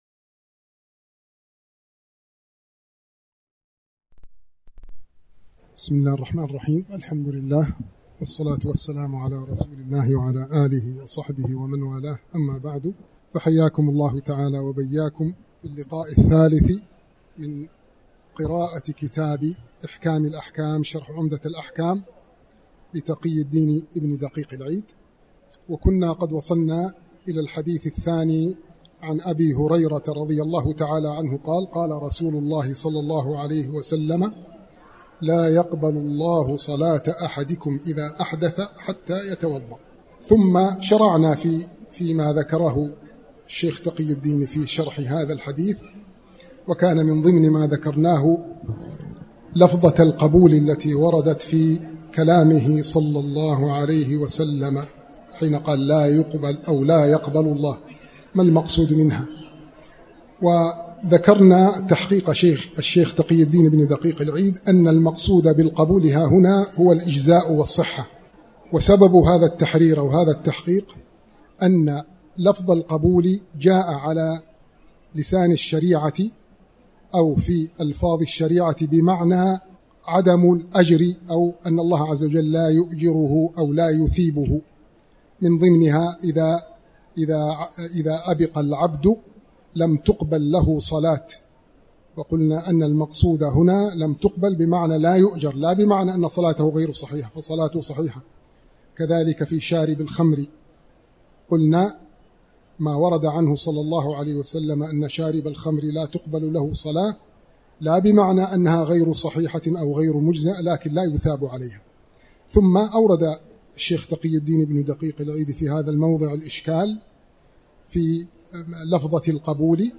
تاريخ النشر ٢٣ شعبان ١٤٣٩ المكان: المسجد الحرام الشيخ